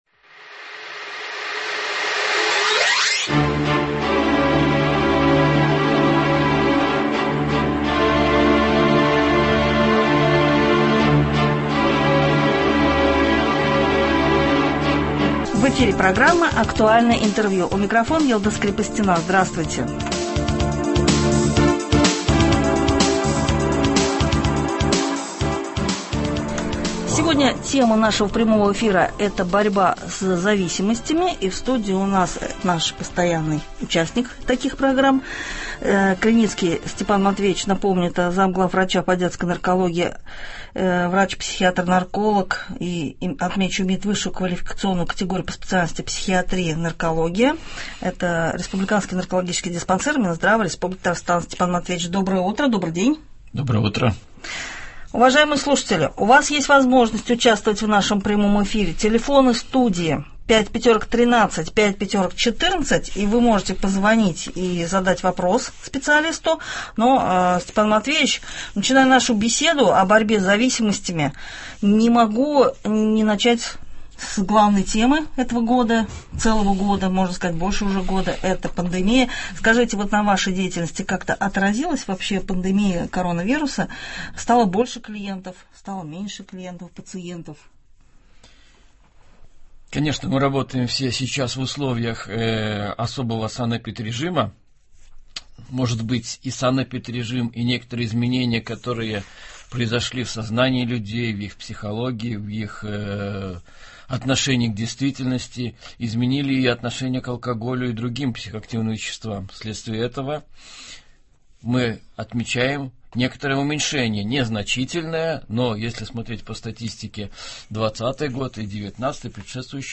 Актуальное интервью (14.04.21)